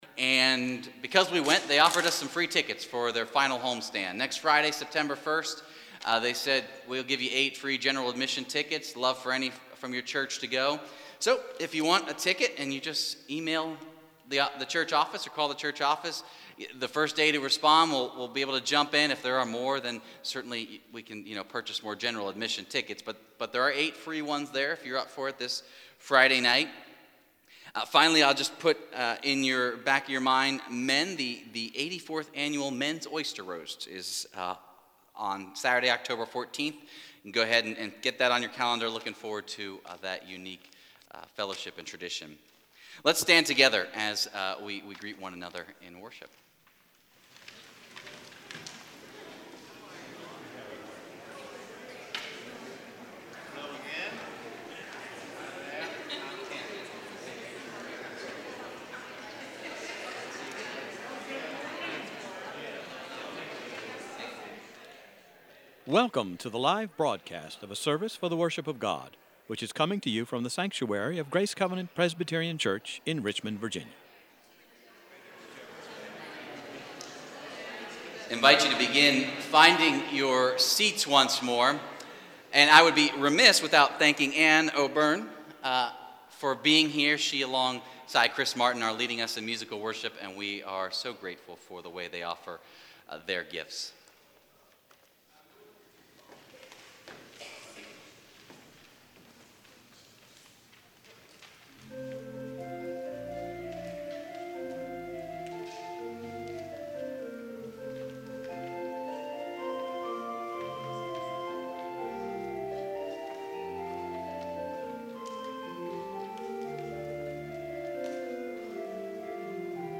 Full Services - Grace Covenant Presbyterian Church
PRELUDE Allegretto (Organ Sonata No. 4, Op. 65) Felix Mendelssohn, 1809-1847
PRELUDE Where Shall I Flee (BWV 694) Johann Sebastian Bach, 1685-1750